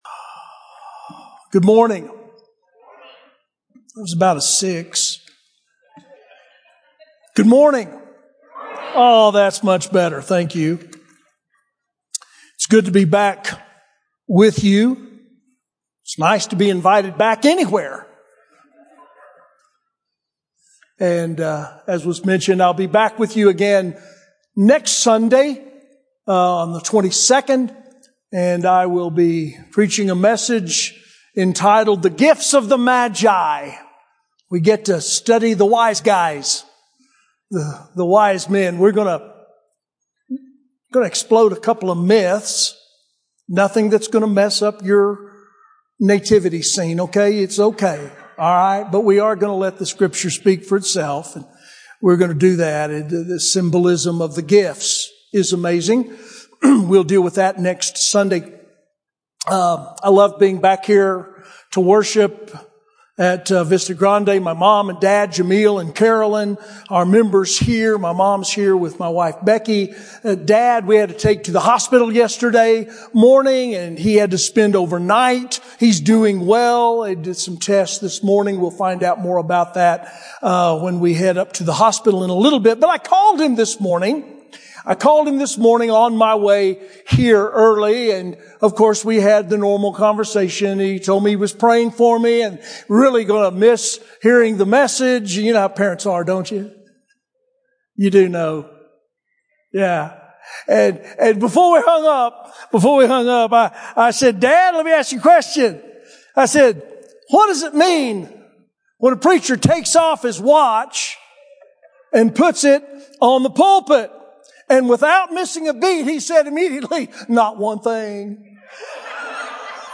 Sermons - Vista Grande Baptist Church
Guest Speaker